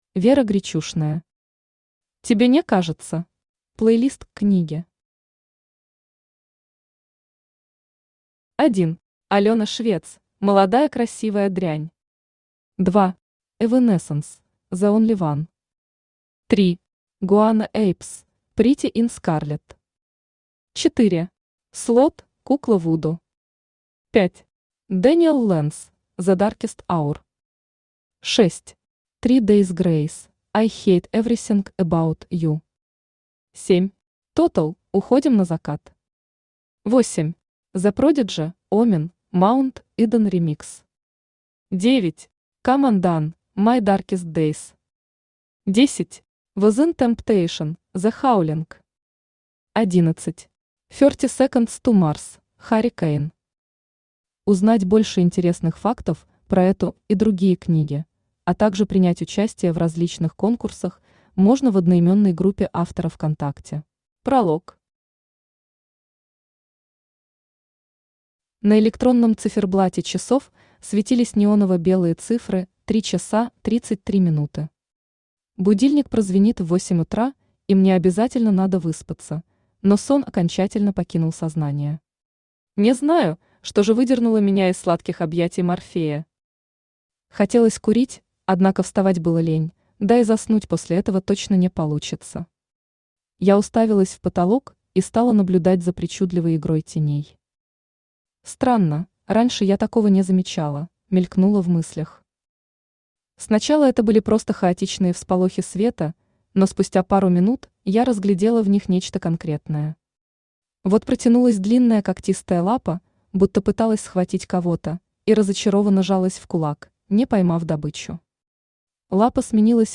Аудиокнига Тебе не кажется | Библиотека аудиокниг
Aудиокнига Тебе не кажется Автор Вера Денисовна Гречушная Читает аудиокнигу Авточтец ЛитРес.